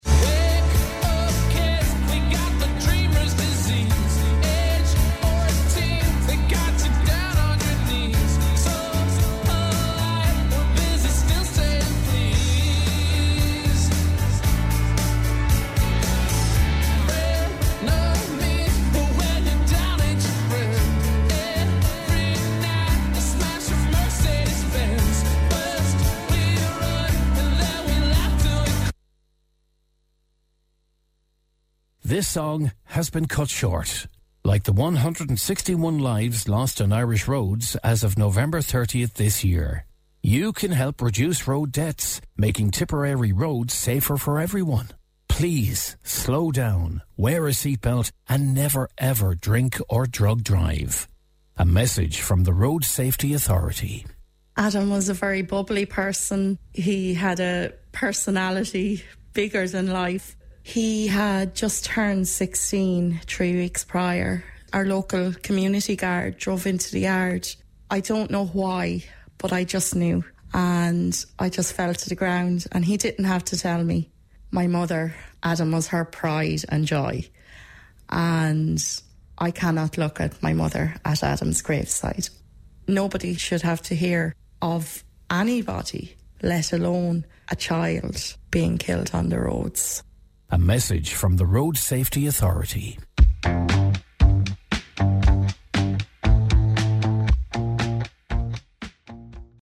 Inserted into regular programming, the campaign used well-known songs that were abruptly cut off mid-track.
Each interruption was followed immediately by a short advertisement reminding listeners that seemingly minor behaviours like checking a phone, speeding or driving under the influence-can have fatal consequences.